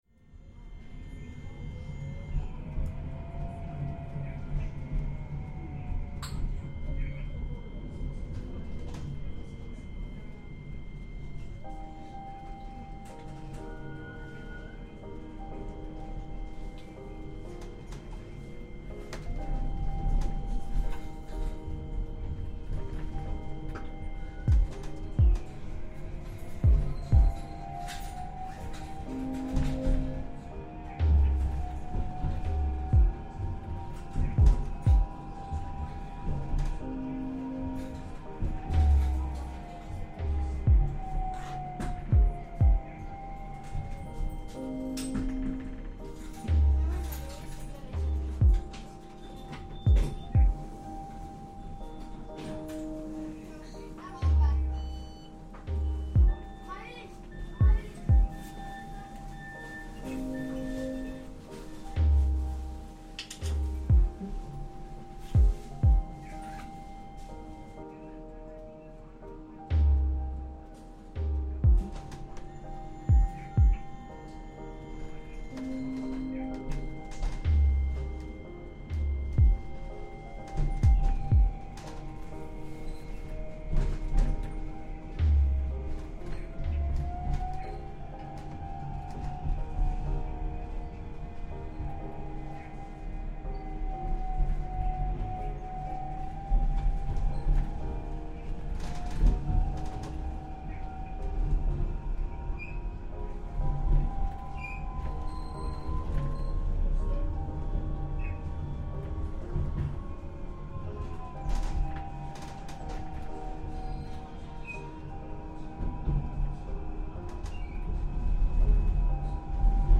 Tram ride ambience
Tallinn tram ride reimagined